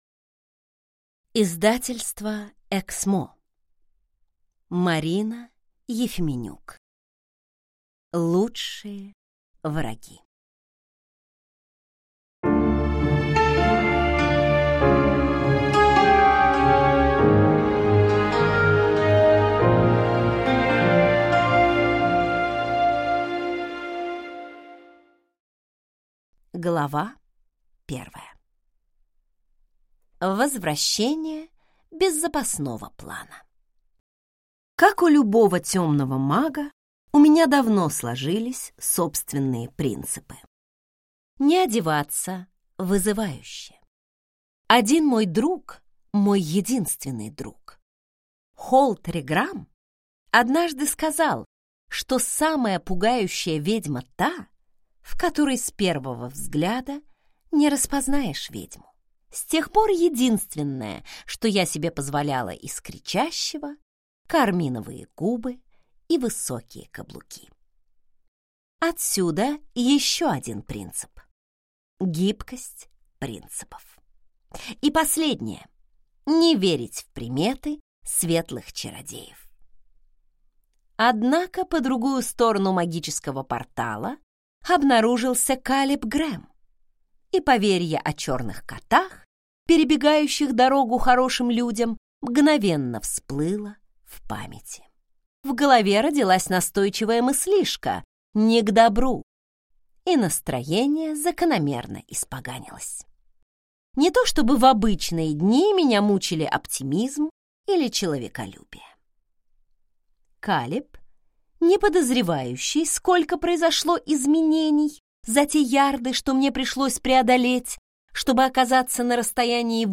Аудиокнига Лучшие враги | Библиотека аудиокниг